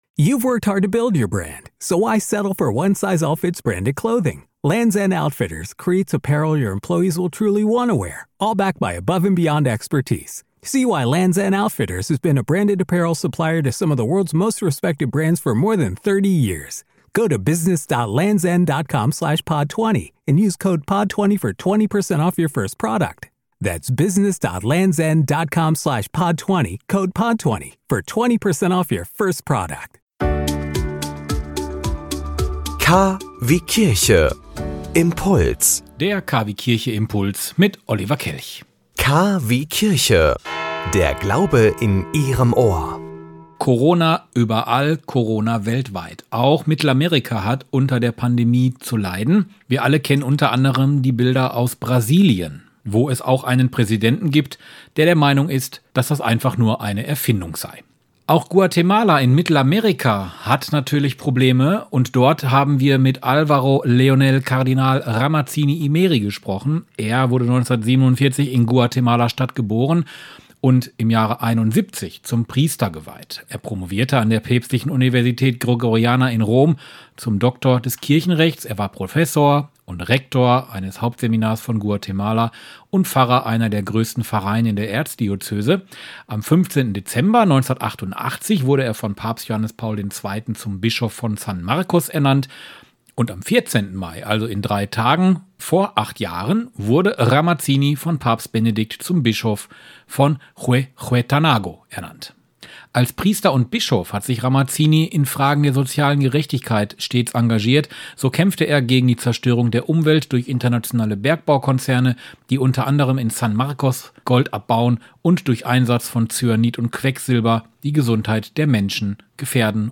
Wie Sie helfen können, was in Guatemala politisch an der Tagesordnung ist und wie Menschen versuchen, mit der Situation klar zu kommen, erzählt der Kardinal in diesem Podcast. Das Interview lief am 11. Mai in KwieKIRCHE bei Radio Vest.